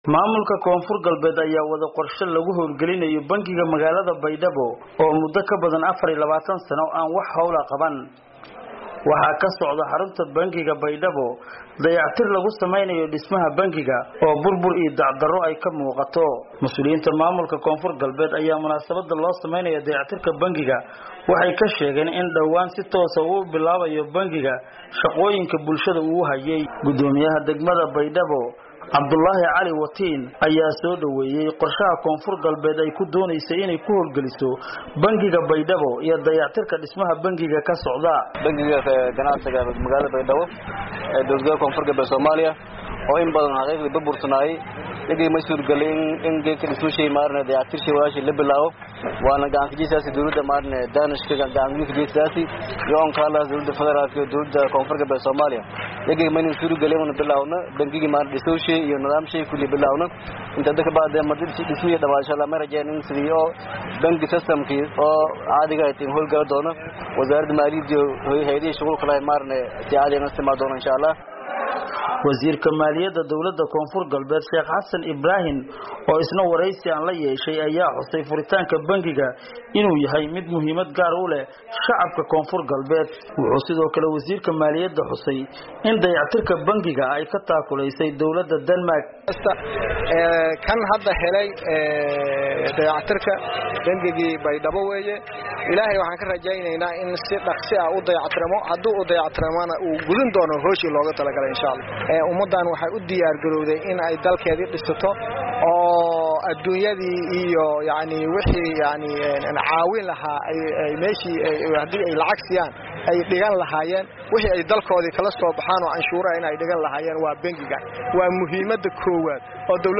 Wasiirka maaliyadda maamulka Koonfur Galbeed ee Somalia Sheekh Xasan Ibrahim, oo wareysi siiyey idaacadda VOA-da, ayaa sheegay in furitaanka Bankiga looga maarmi doono in maamulka uu adeegsado bankiyada sida gaarka ah loo lee yahay.